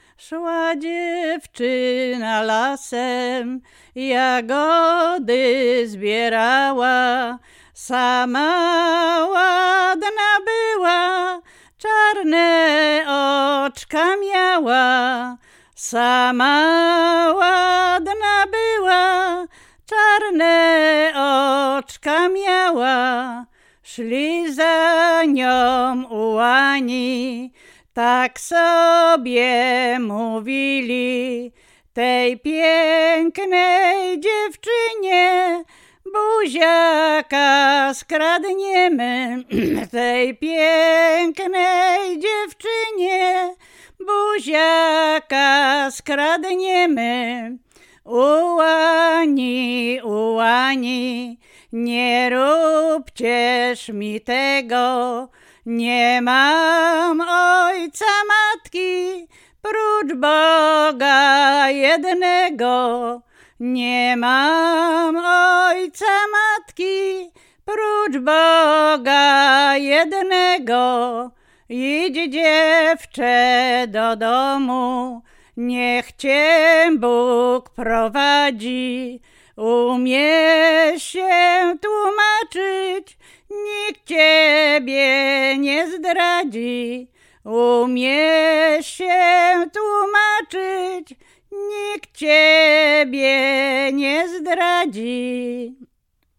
Łęczyckie
liryczne